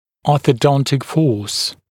[ˌɔːθə’dɔntɪk fɔːs][ˌо:сэ’донтик фо:с]ортодонтическая сила